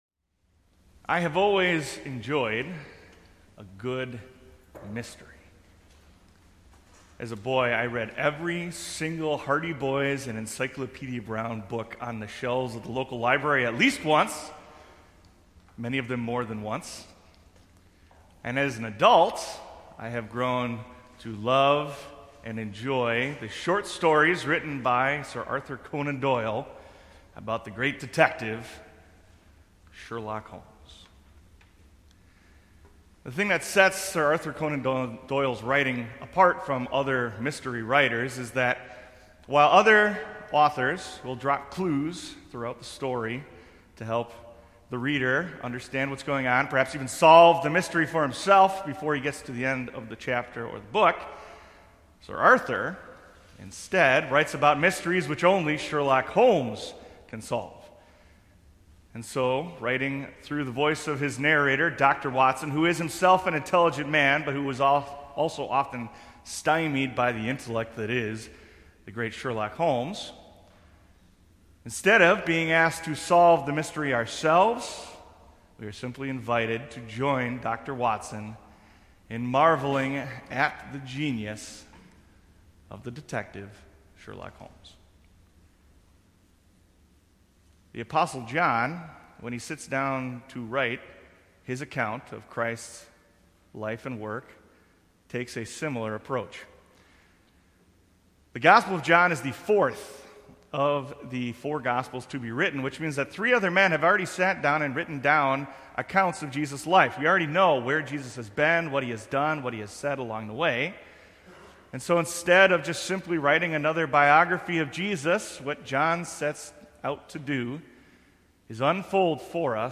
Sermons from Faith Evangelical Lutheran Church (WELS) in Antioch, IL